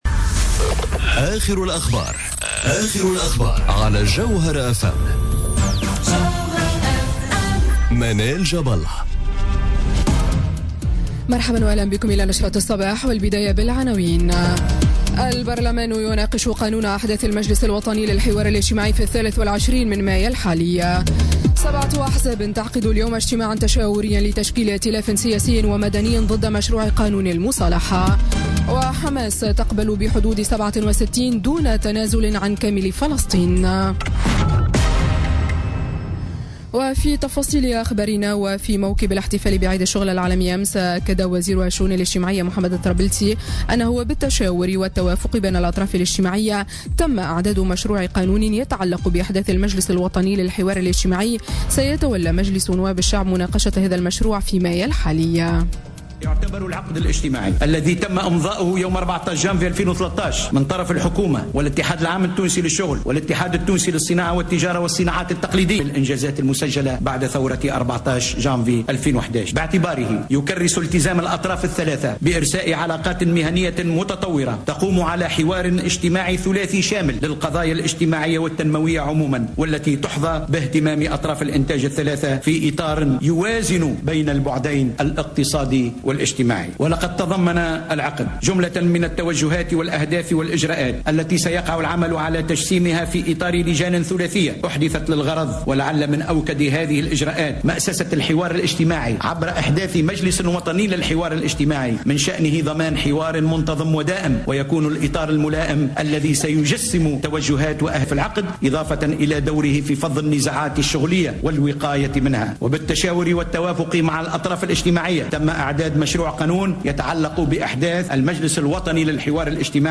نشرة أخبار السابعة صباحا ليوم الثلاثاء 2 ماي 2017